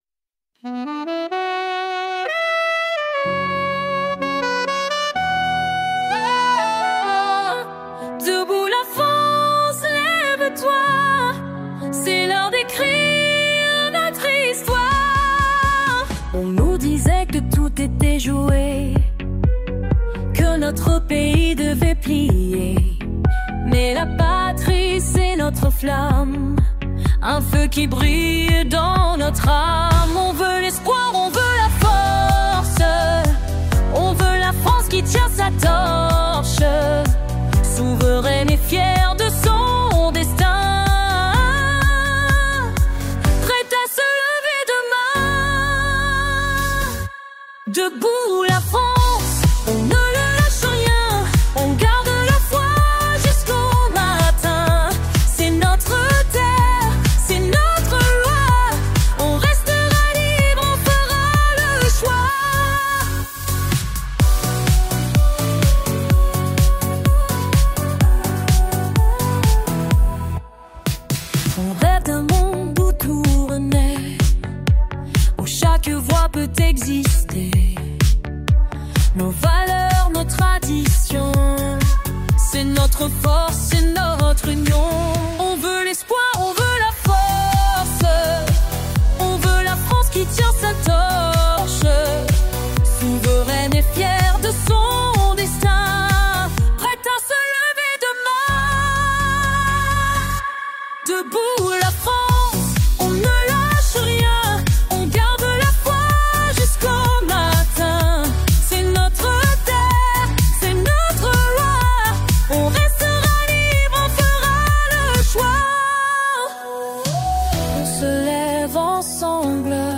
en utilisant l’intelligence artificielle
Ces airs entraînants, qui restent immédiatement en tête, donnent une nouvelle manière de militer : moderne, créative et accessible à tous.